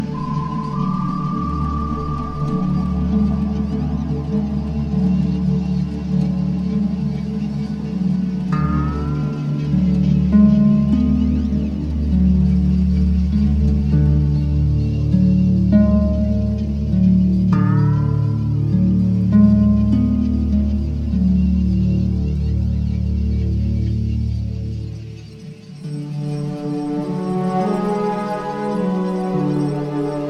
bande originale du film d'animation
"templateExpression" => "Musique de film"